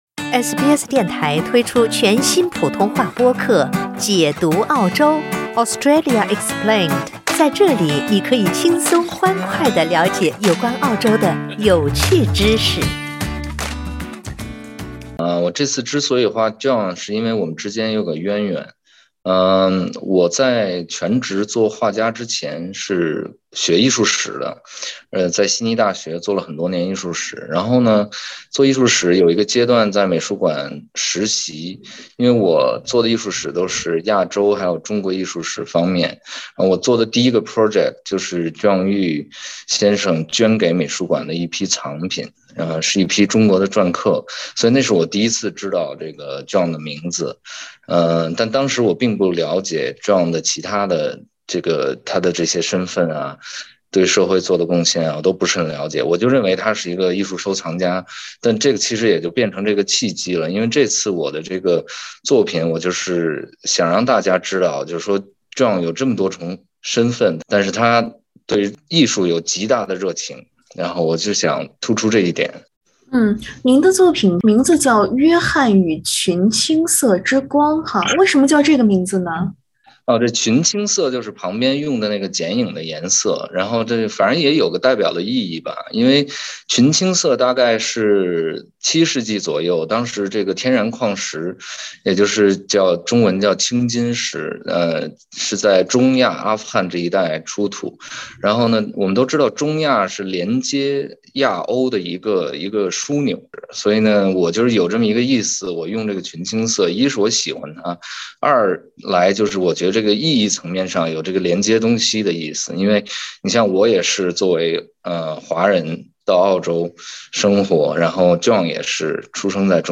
（點擊上圖收聽寀訪）